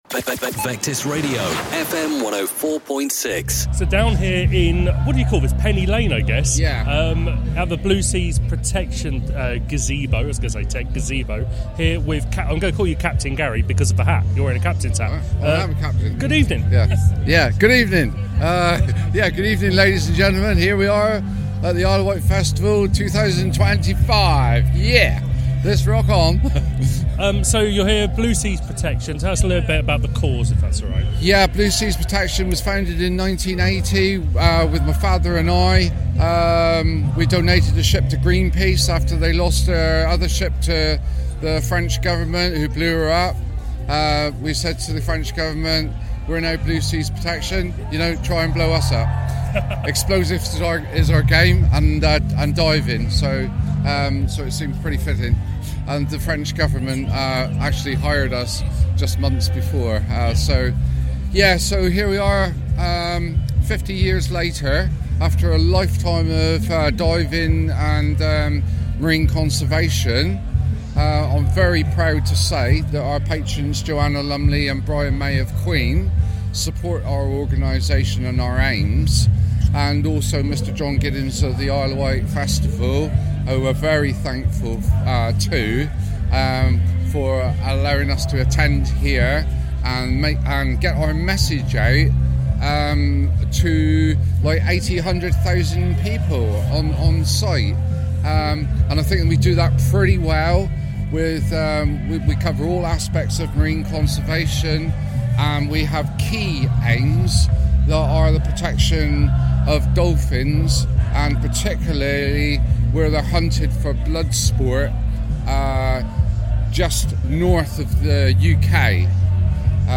at this year's Isle of Wight Festival.